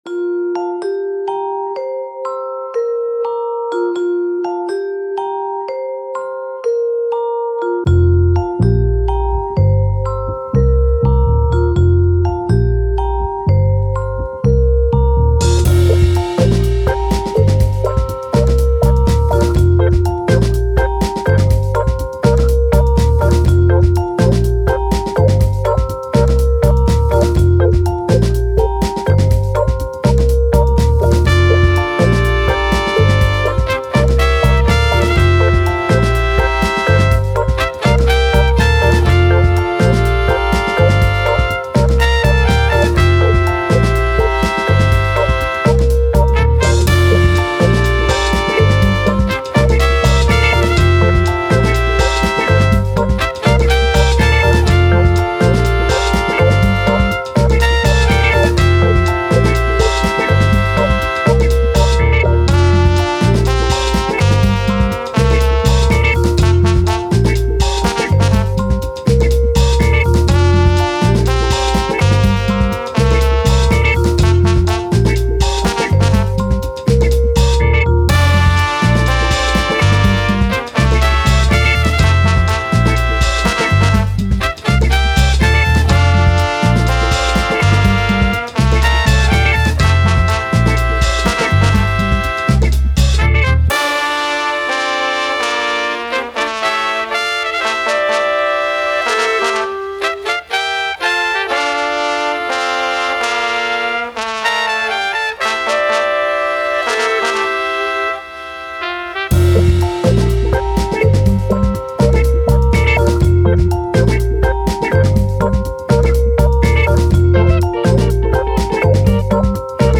Afrobeat, Upbeat, Playful, Sun